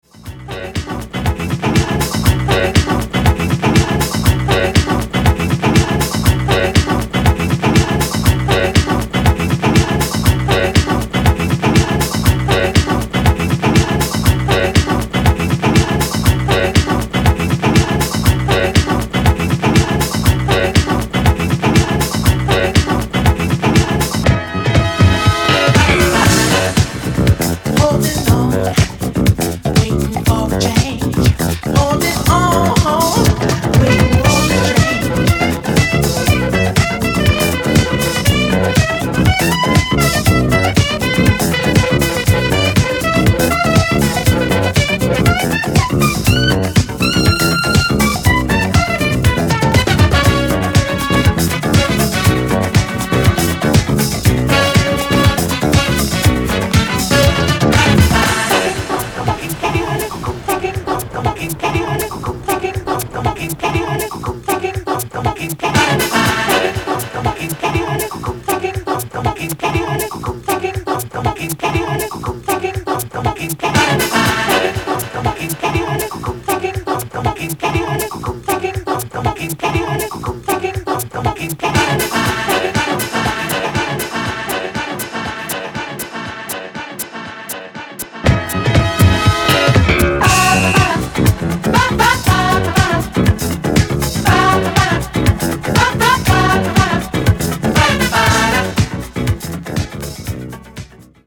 リエディット